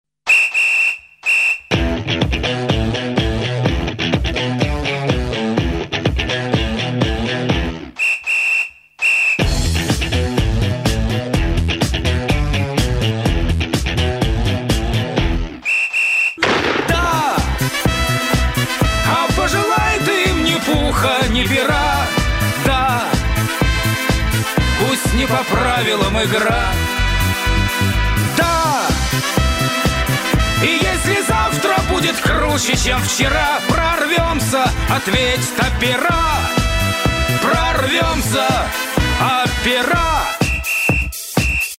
Категория: Поп музыка, Eurodance